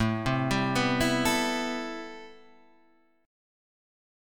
A Minor 9th